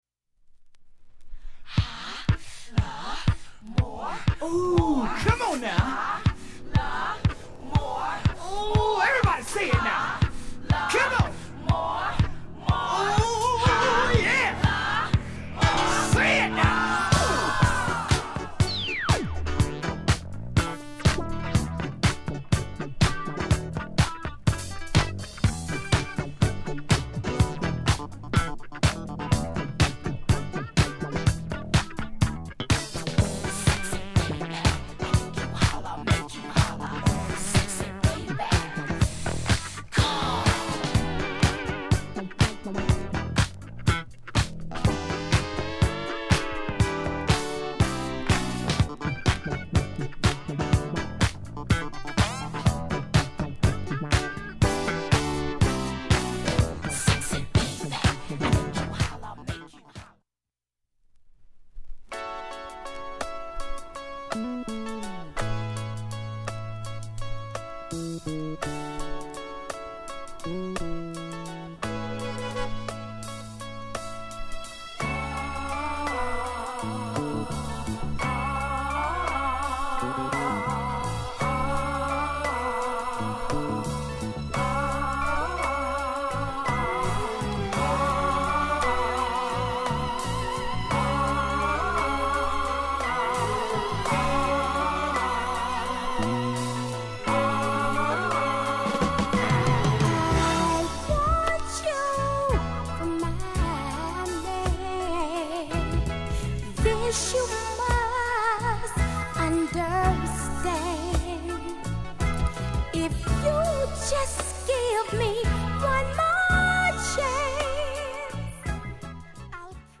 ほかA-1途中2ミリ弱薄いキズでプツ出ますが
shure 44g 針圧１，５ｇで全曲試聴済み
現物の試聴（両面はじめとA-2キズ部）できます。音質目安にどうぞ